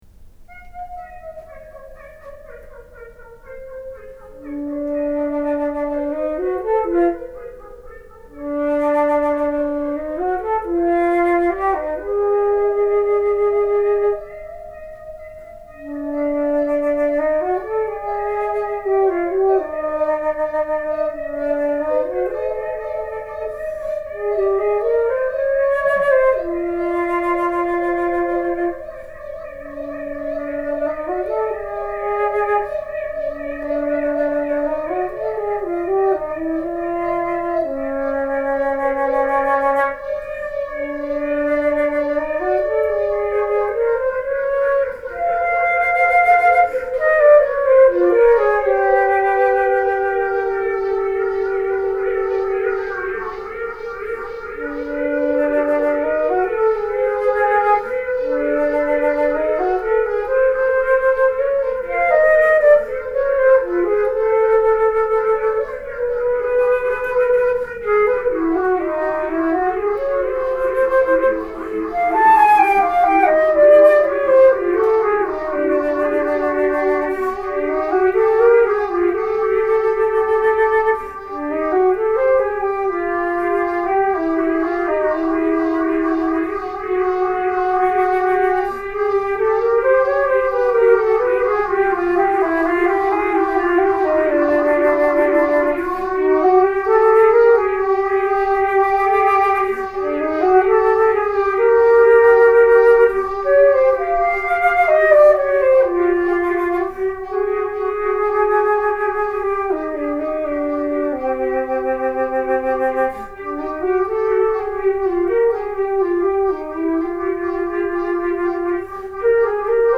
Theremin
Flute.
(Spontaneous improvisation.)